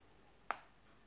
clac.m4a